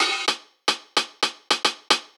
Index of /musicradar/ultimate-hihat-samples/110bpm
UHH_AcoustiHatA_110-01.wav